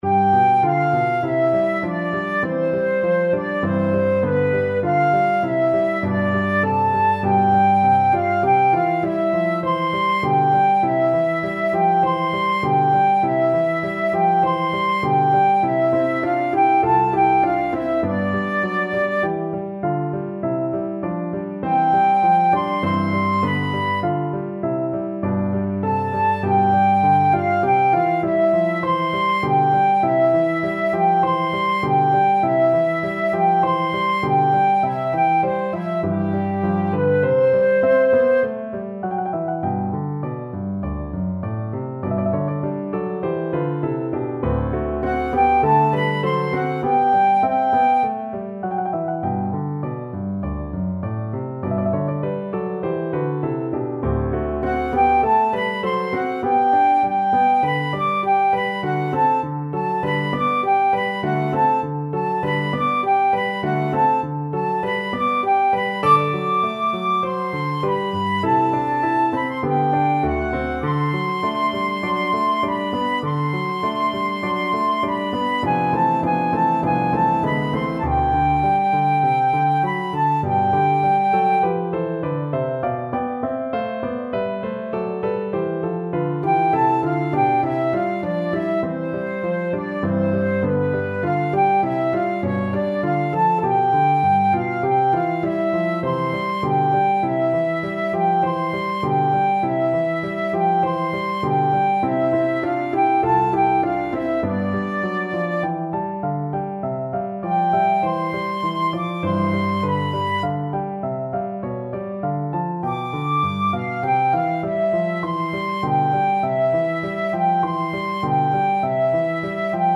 2/4 (View more 2/4 Music)
G5-F7
Classical (View more Classical Flute Music)